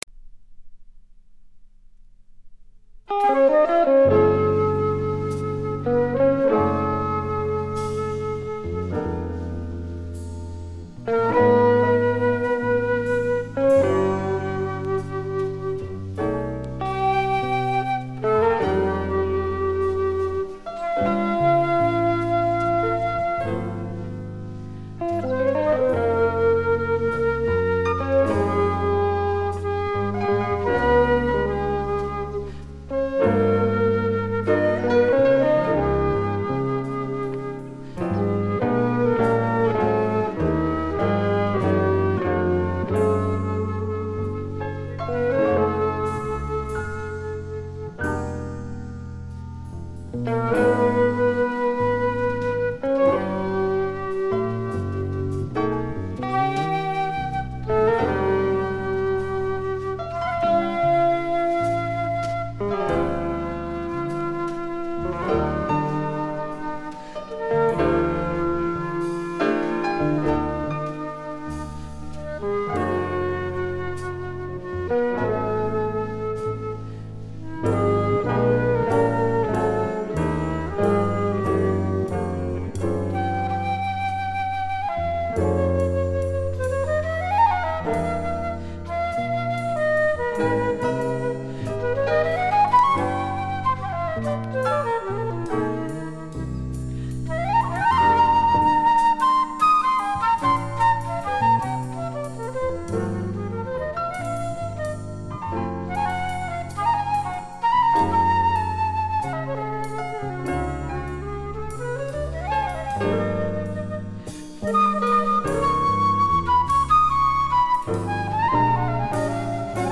Flûte, piano, guitare, bass, drums / flute & guitare solos